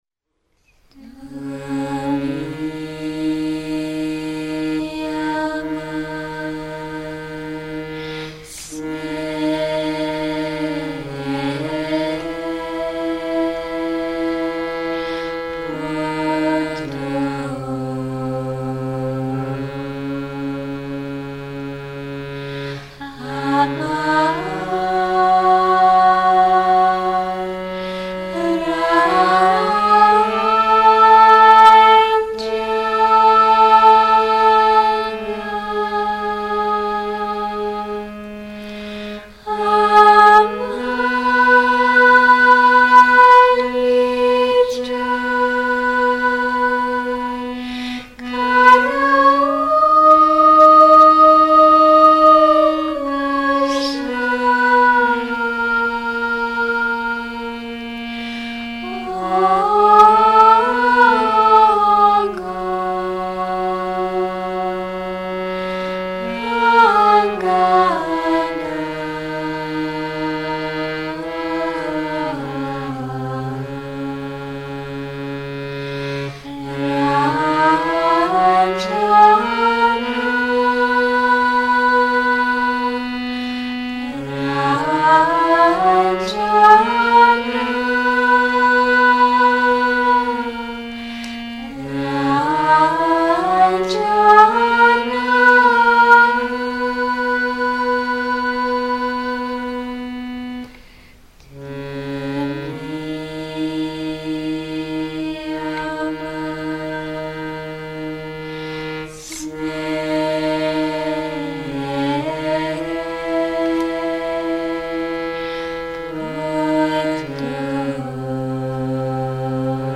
Various students of Sri Chinmoy sing every 1000th song composed by Sri Chinmoy. This recording includes thirteen songs from song number 1 (Tamasa Rate Nayan Pate) to song number 13,000 (Shakpura Shakpura).